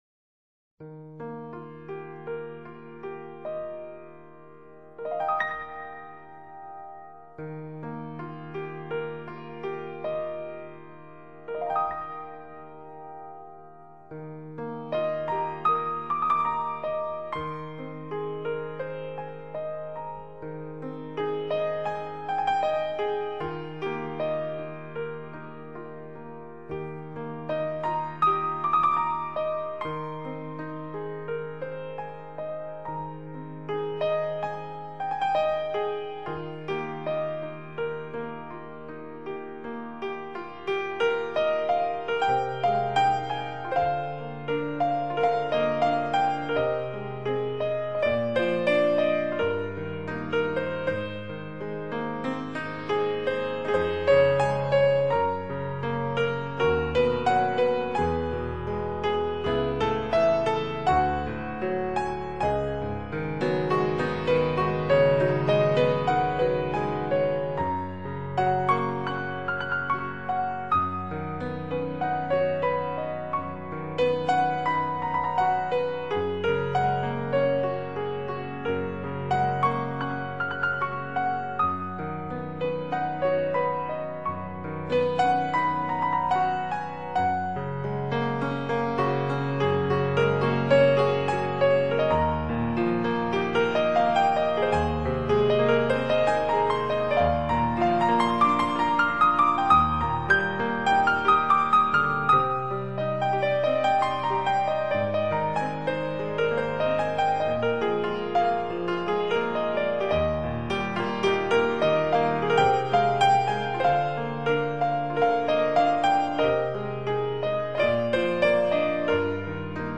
给我的感觉是清冽和硬性的，犹如敲击在铁片上的清脆，干净利落。